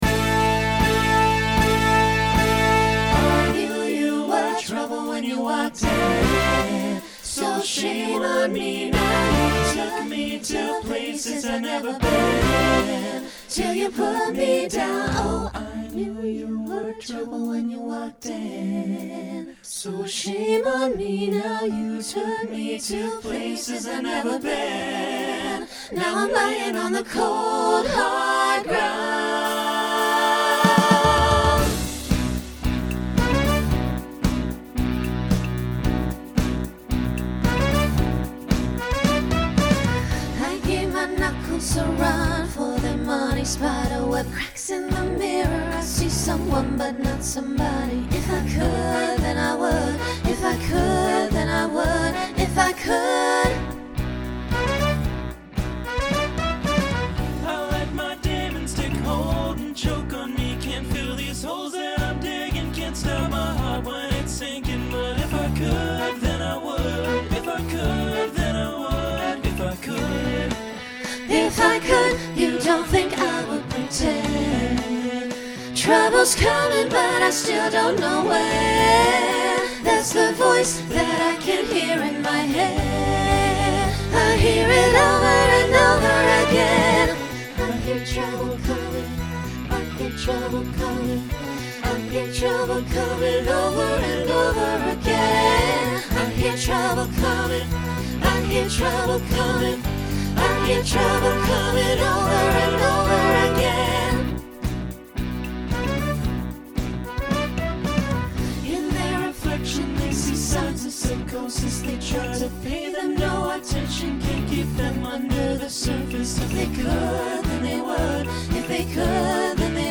Genre Pop/Dance , Rock
Transition Voicing SATB